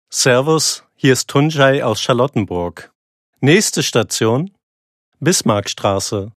Sprechprobe: Industrie (Muttersprache):
BVG Ansage.MP3